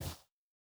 Shoe Step Snow Medium A.wav